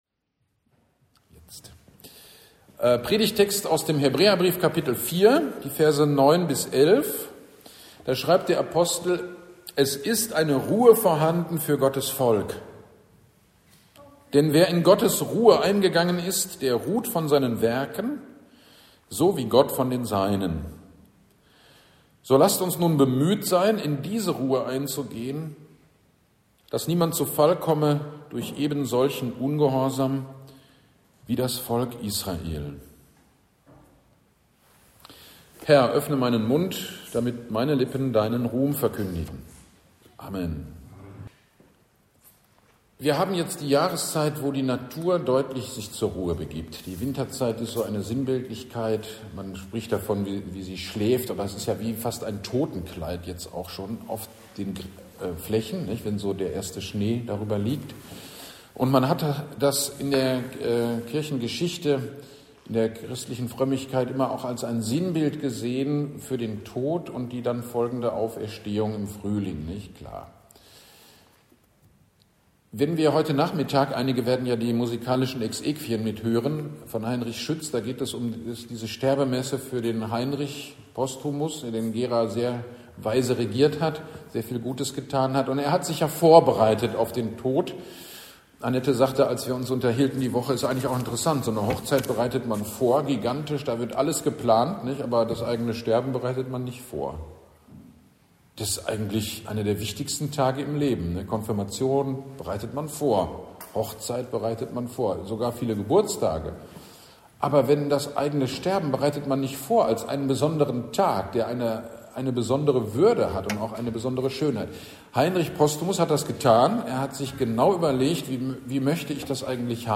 GD am 23.11.25 – Ewigkeitssonntag – Predigt zu Hebräer 4, 9-11